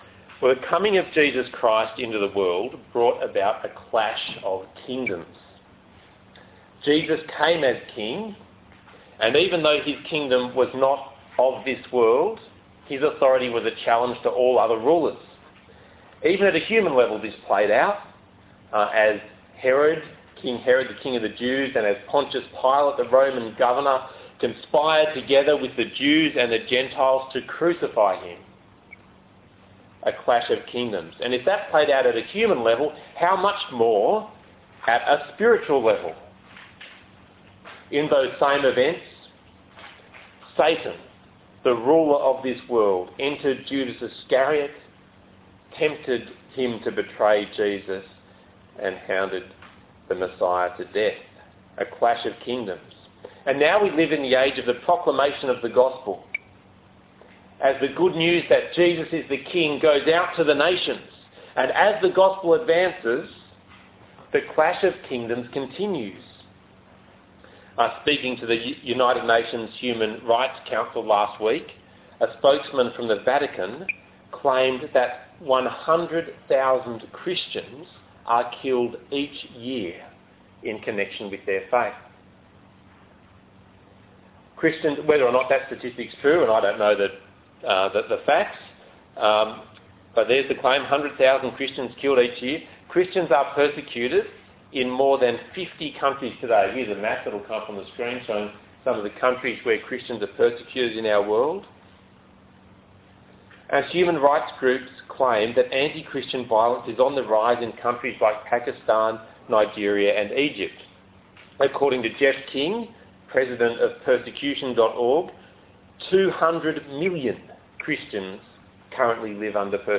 The Kings and I Passage: 1 Samuel 21:1-22:23 Talk Type: Bible Talk « 1 Samuel 20